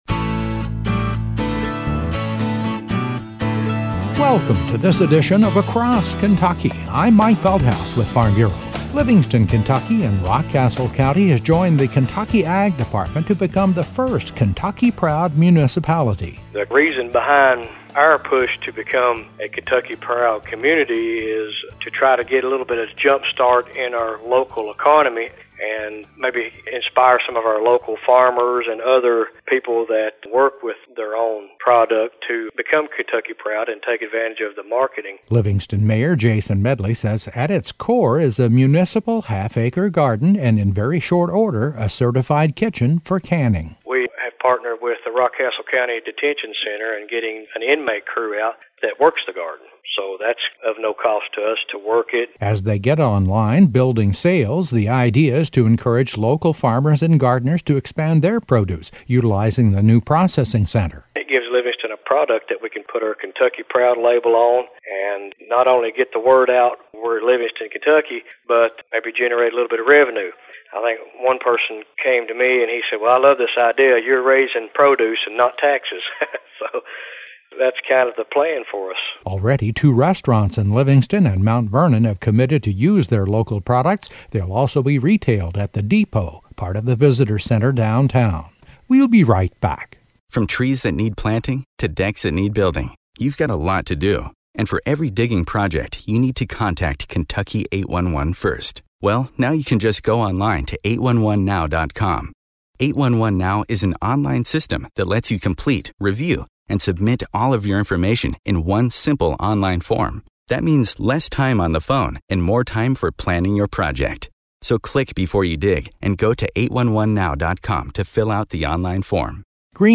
Across KentuckyA report on the community of Livingston, Kentucky which is the state’s first municipality to go “ Kentucky Proud.” Livingston Mayor, Jason Medley says, at its heart, the effort is an economic development plan to use gardening, a municipal-based canning program, and Kentucky Proud marketing to boost income in the local area.